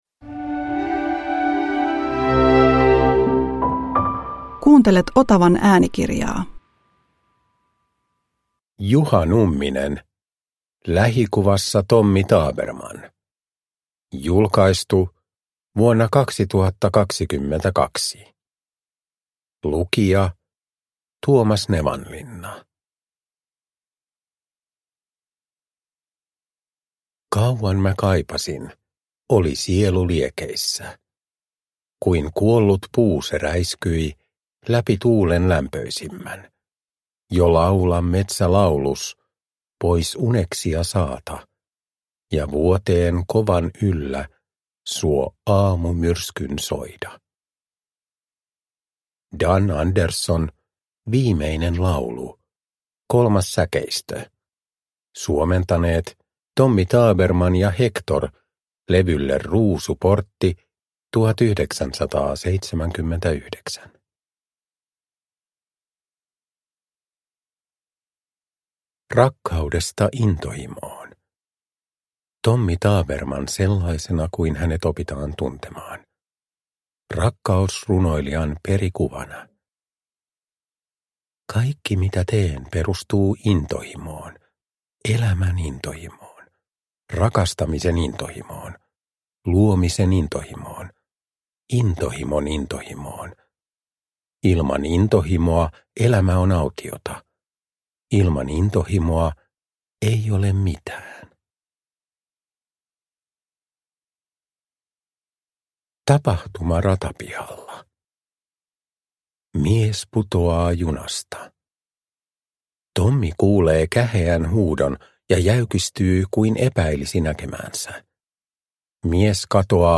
Lähikuvassa Tommy Tabermann – Ljudbok – Laddas ner